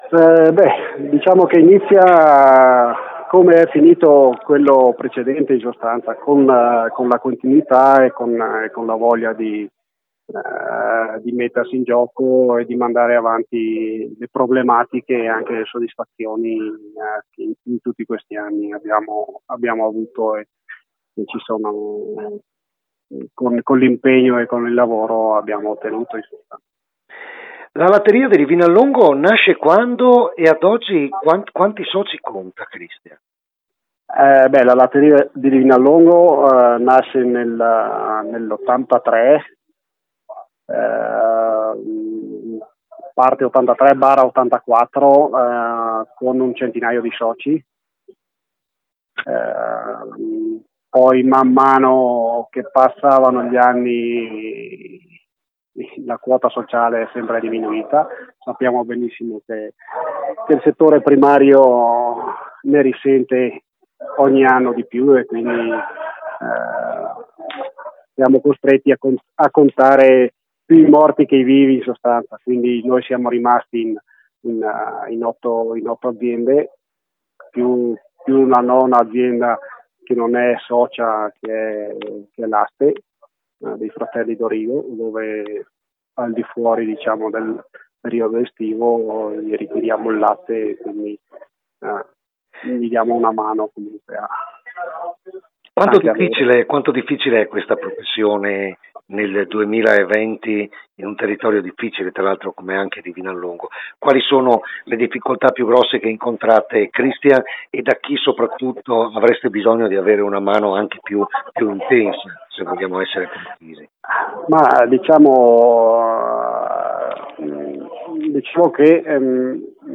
AI MICROFONI DI RADIO PIU